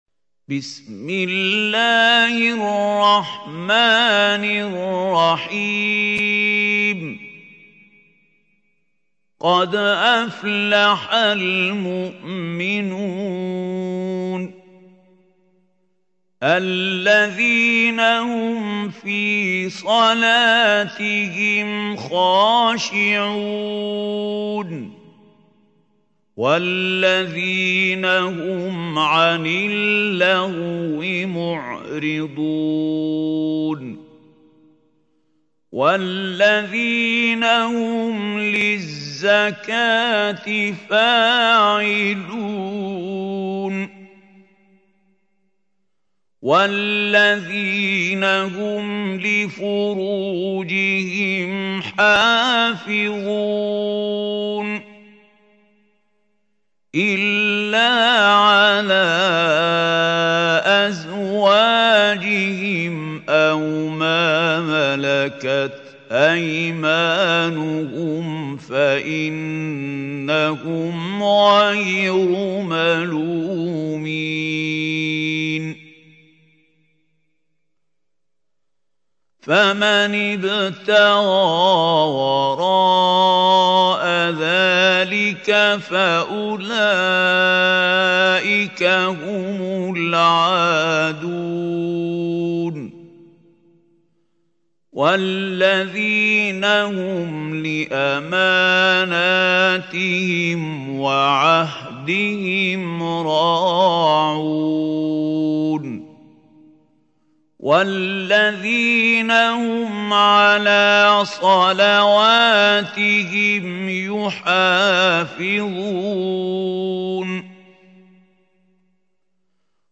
سورة المؤمنون | القارئ محمود خليل الحصري